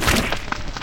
PixelPerfectionCE/assets/minecraft/sounds/mob/magmacube/small1.ogg at mc116